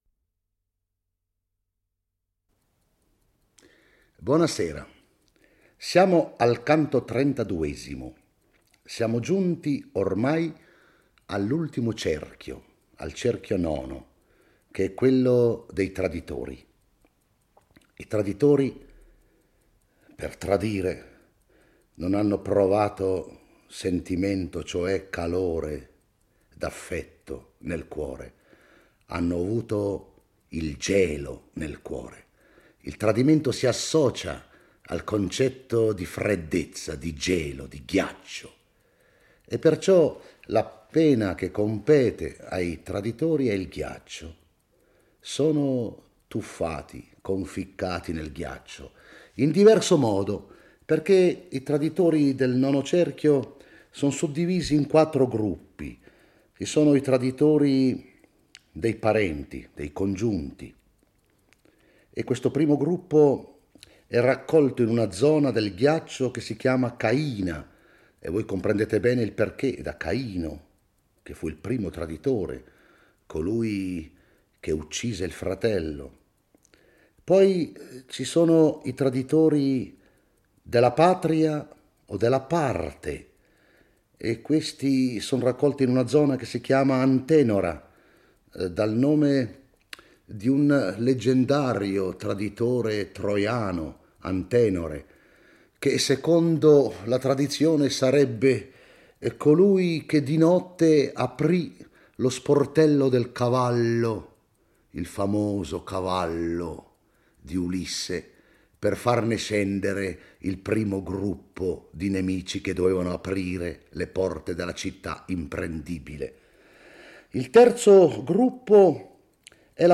legge e commenta il XXXII canto dell'Inferno